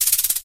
byron_reload_01.ogg